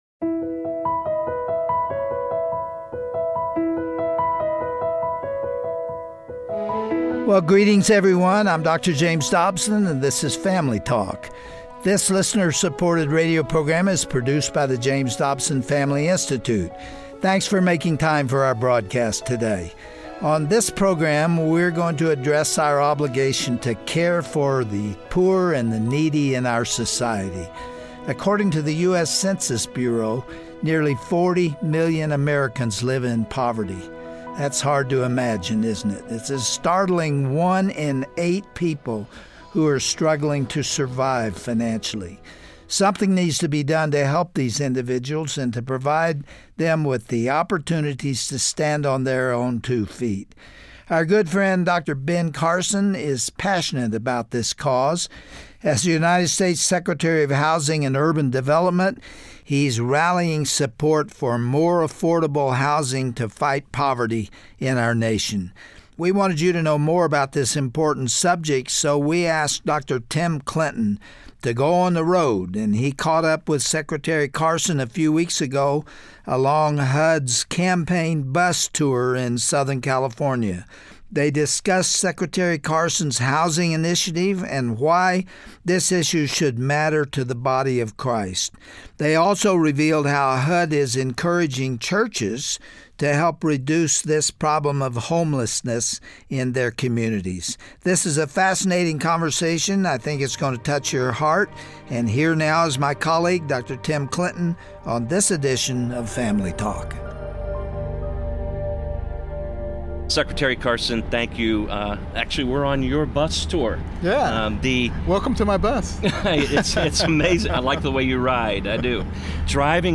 Through this Family Talk broadcast, youll understand why believers must continue to follow His example.